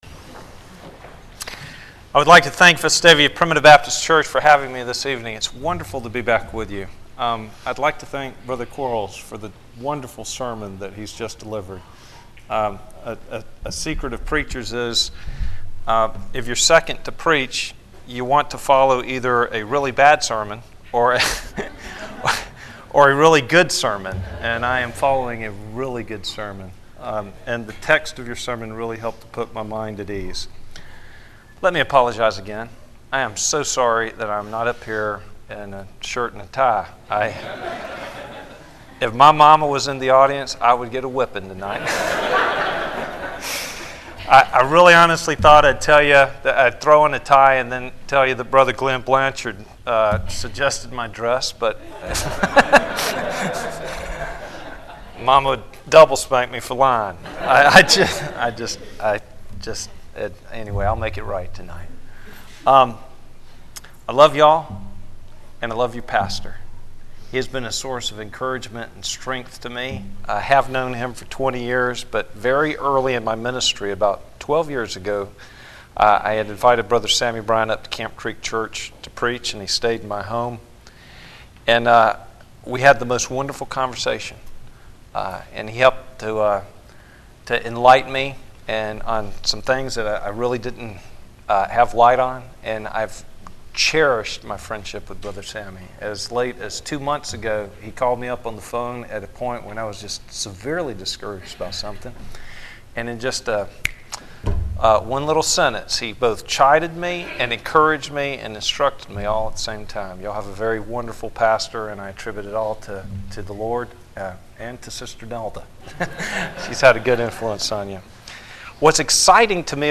Sermons - Guest Preachers